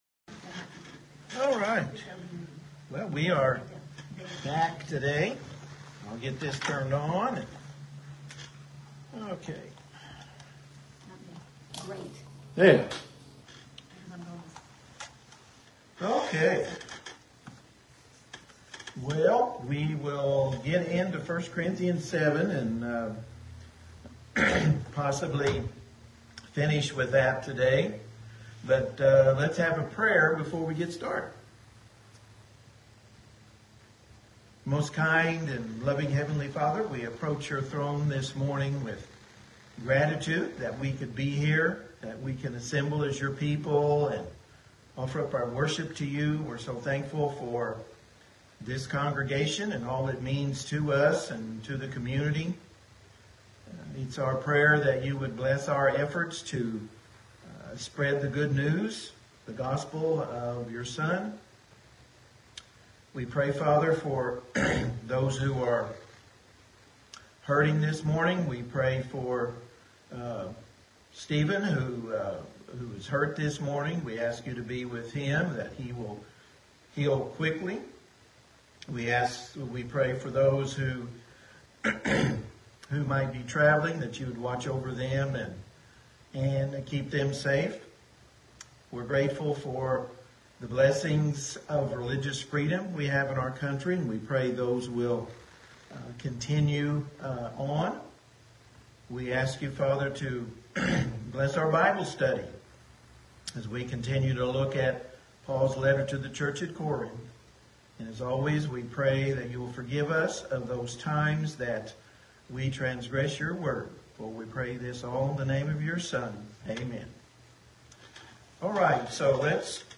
Church of Christ sermon - Marriage and the Present Distress